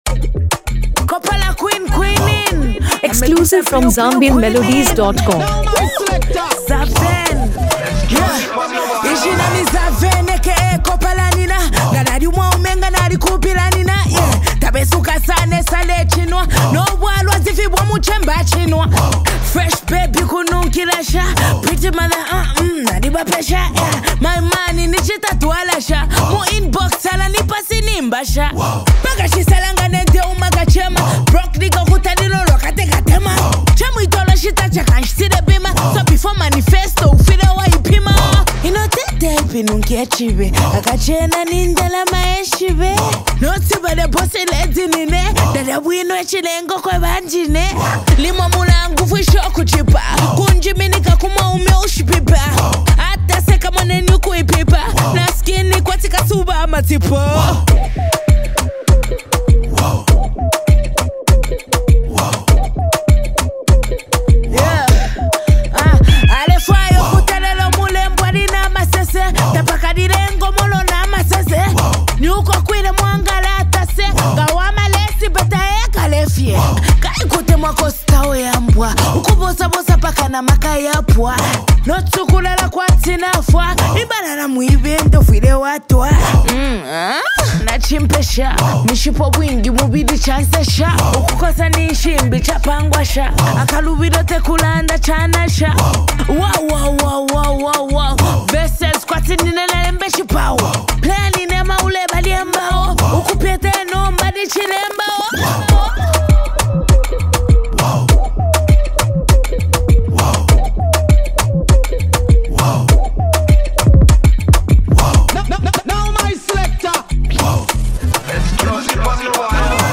Fans have praised its addictive hook, vibrant production